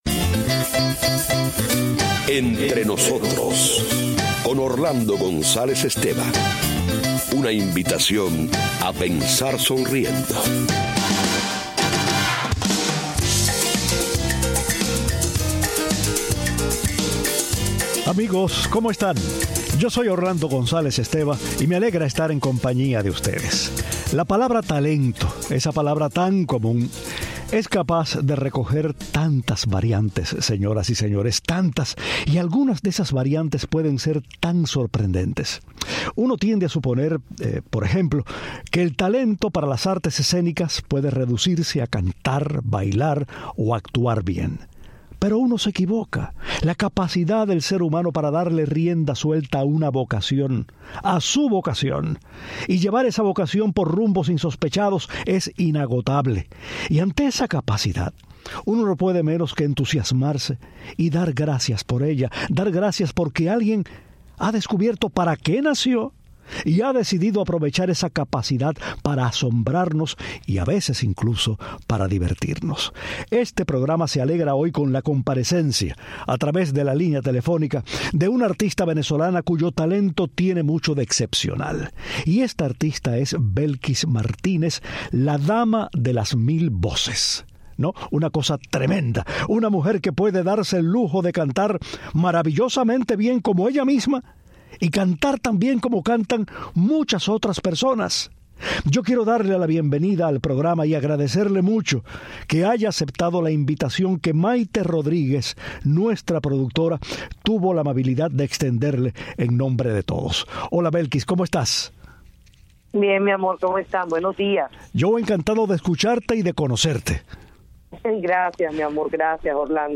habla de su vida, su carrera, del arte de imitar y la escuchamos "convertirse" en Ana Gabriel, Isabel Pantoja, Vicky Carr y otras intérpretes destacadas.